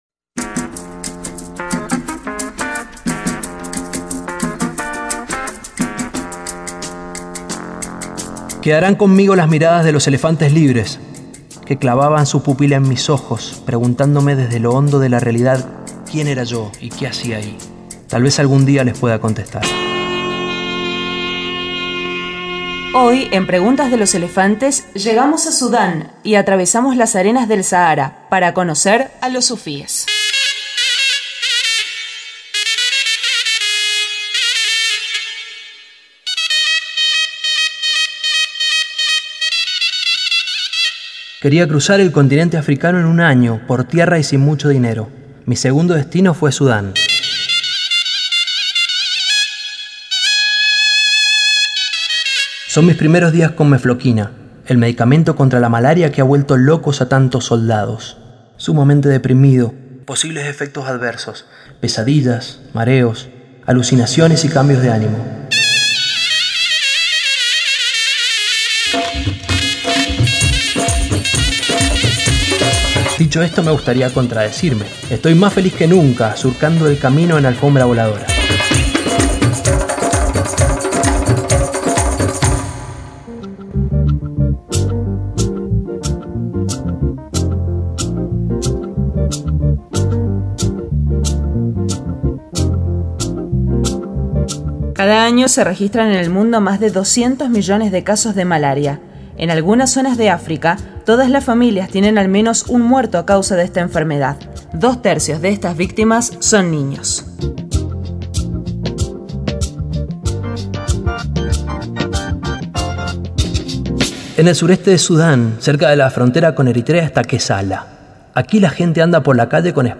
Headliner Embed Embed code See more options Share Facebook X Subscribe Cap. 2 - Sudán: una visita a los sufíes de Kessala Esta vez, comparto una pequeña experiencia -¿mística?- con los sufíes de Sudán. Relatos, canciones y aventuras para salir un poco del cubo en que vivimos.